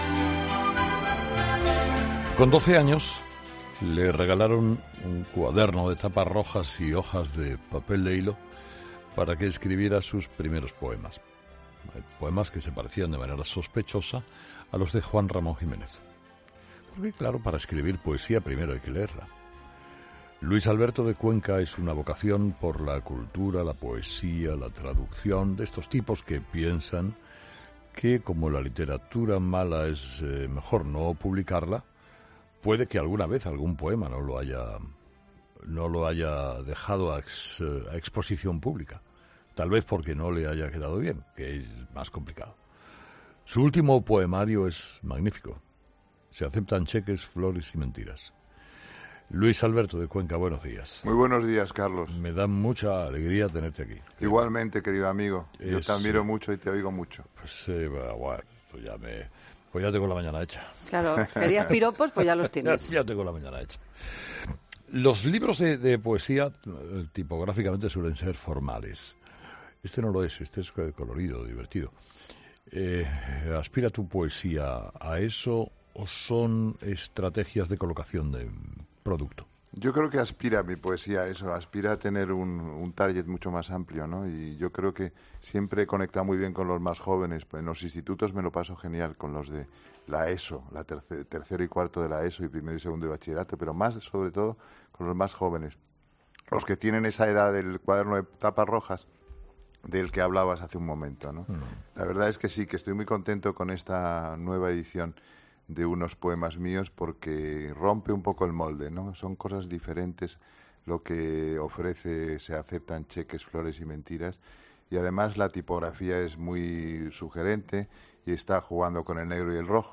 El escritor Luis Alberto de Cuenca, en Herrera en COPE